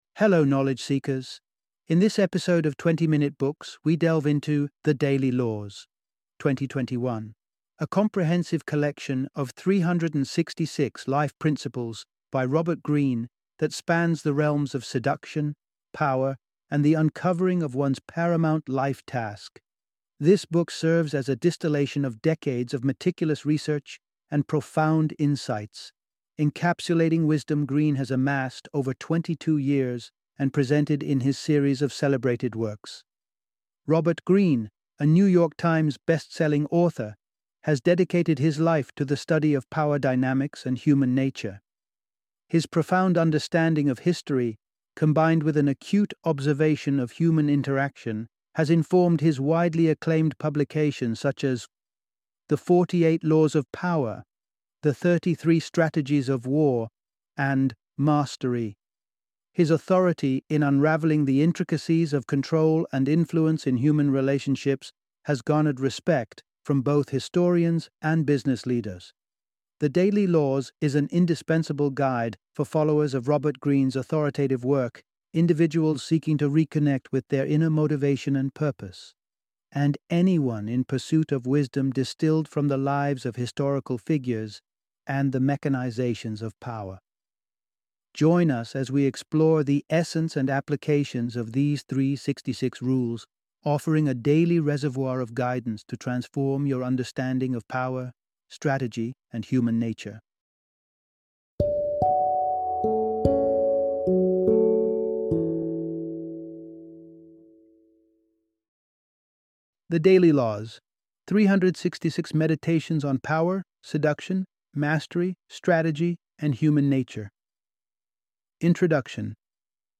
The Daily Laws - Audiobook Summary